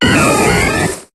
Cri d'Empiflor dans Pokémon HOME.